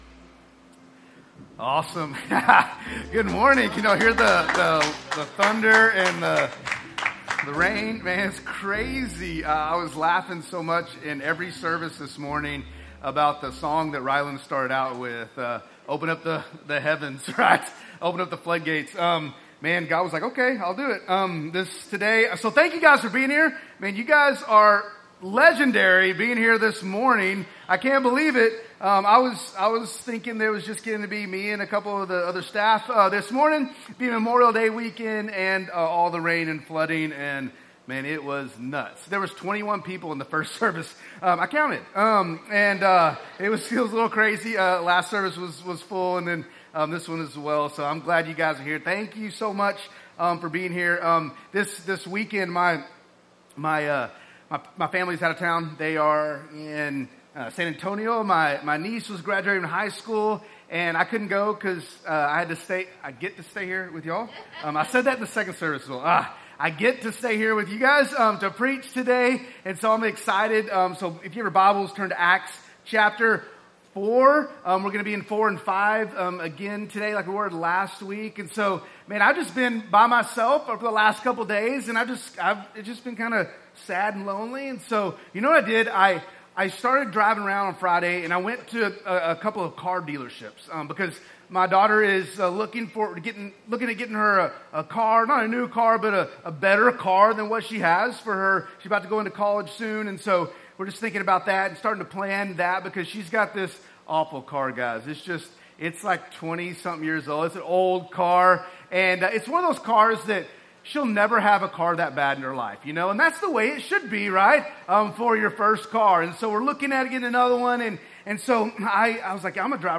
"So They May Hear," our summer sermon series deep diving into the book of Acts